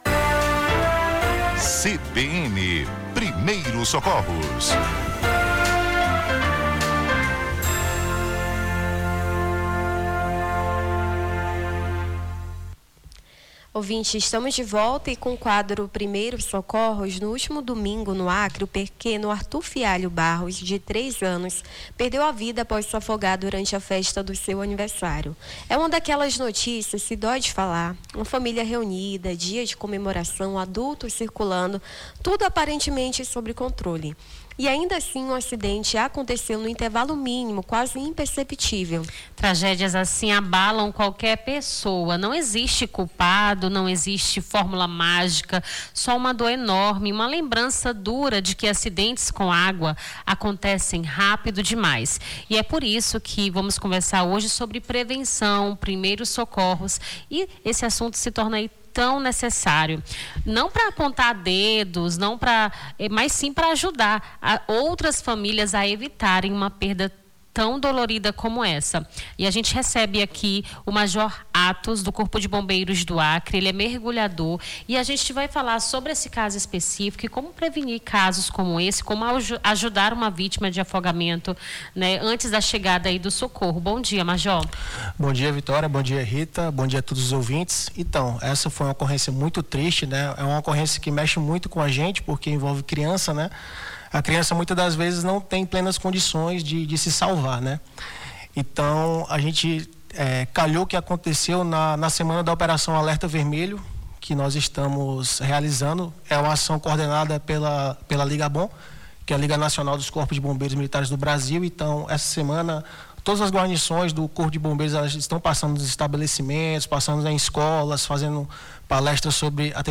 recebemos no nosso stúdio da rádio CBN Amazônia